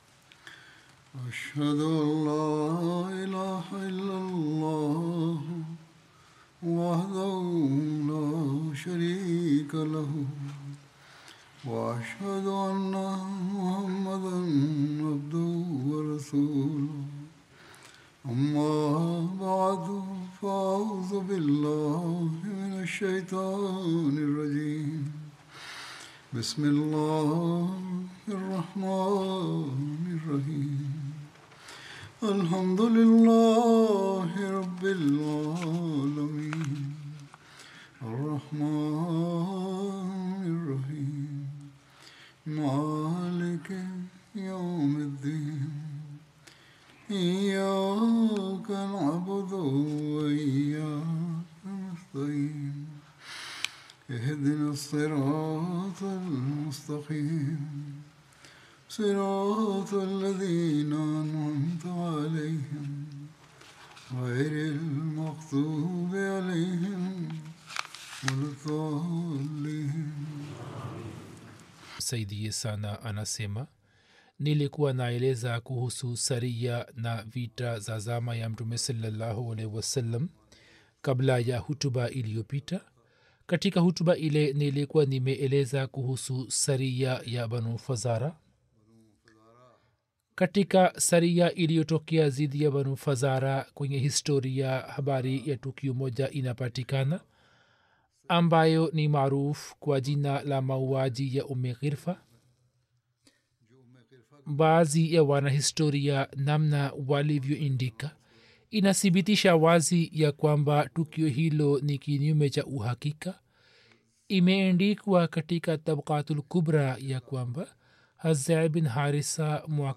Swahili translation of Friday Sermon